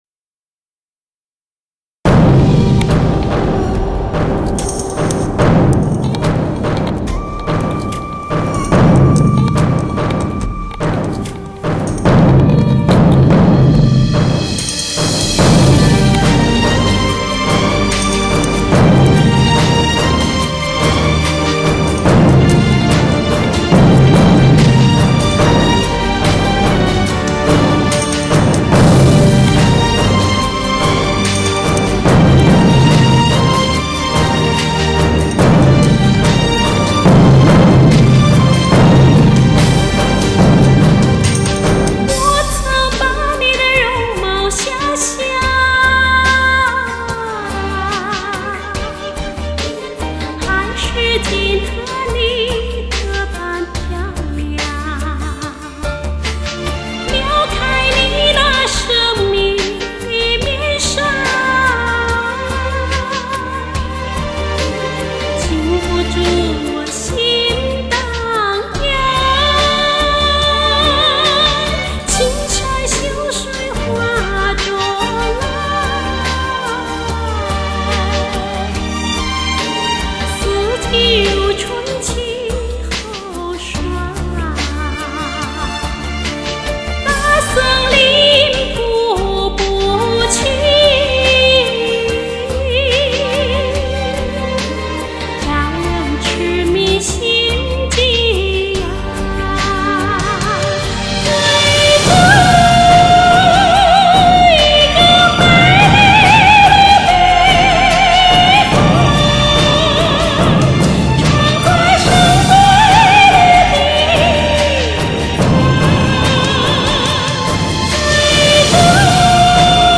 山歌演唱欣赏
布依族山歌的字数并不固定，有长有短，有多有少。